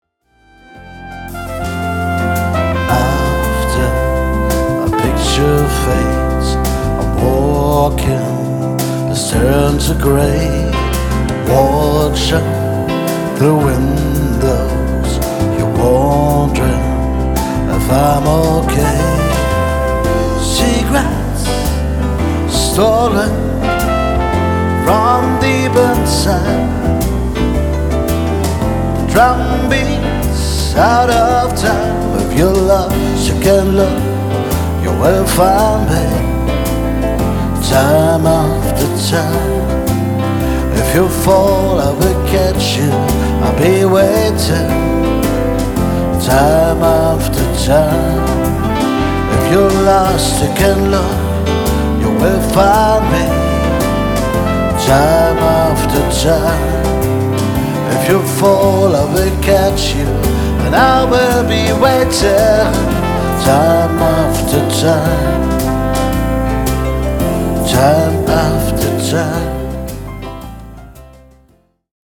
Demoaufnahmen